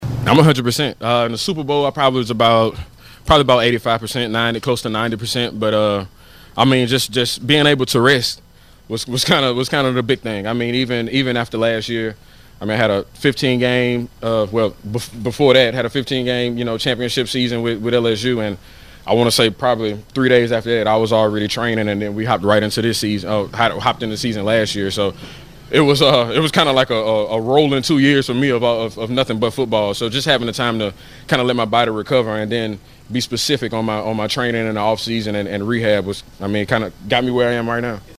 Clyde Edwards Helaire visits with the media Thursday after practice.